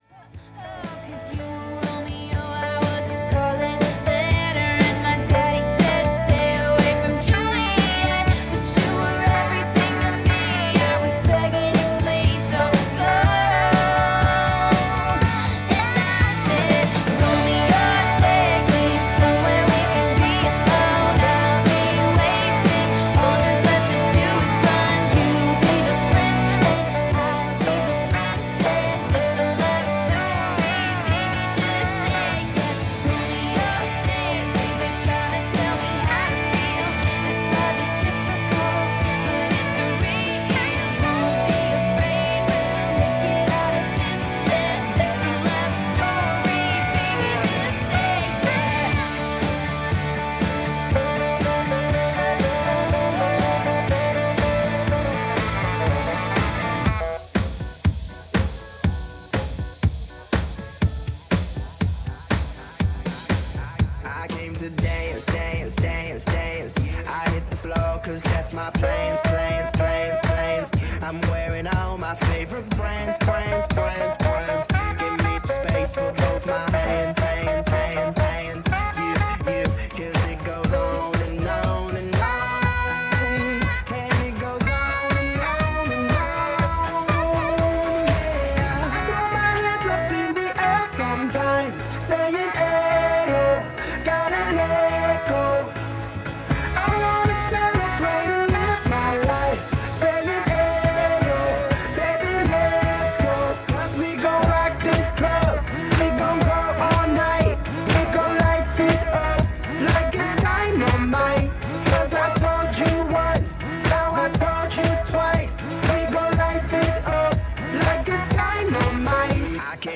A live wedding mix by Neon Transit recorded March 2026.